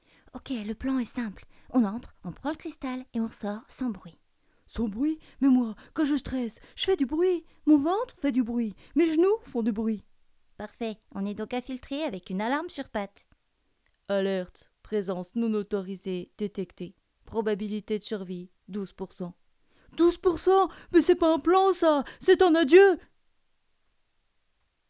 Voix off féminine francophone
Middle Aged